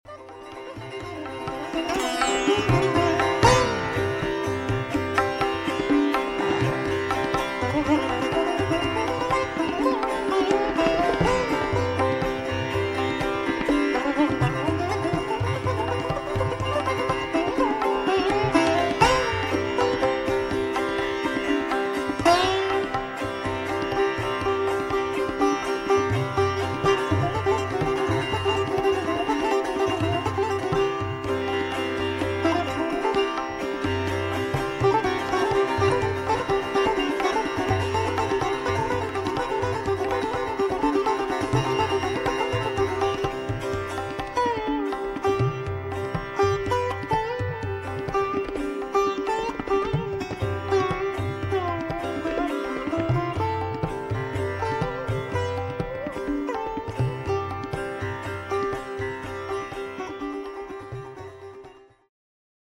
Traditional piece